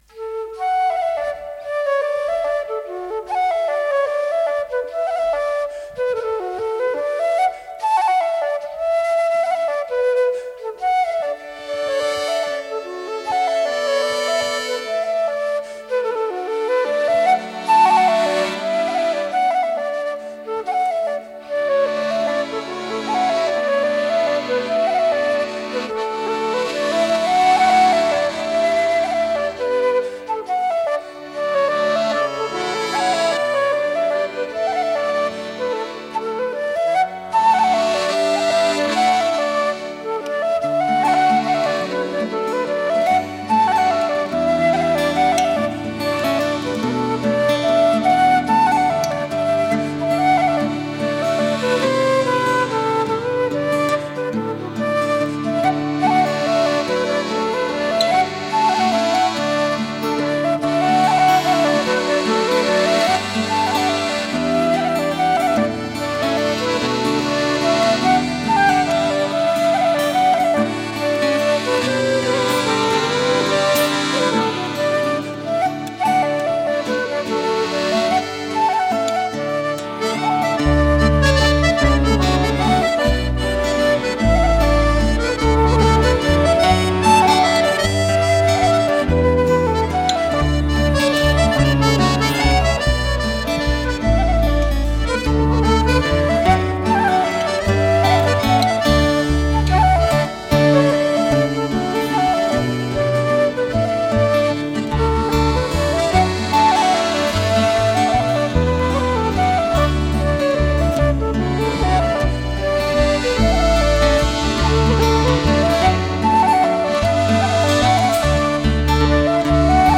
BALSAK